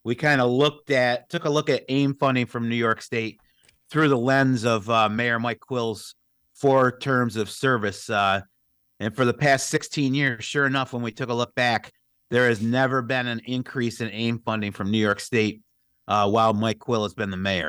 City Clerk Chuck Mason says they took a unique approach with its resolution this year.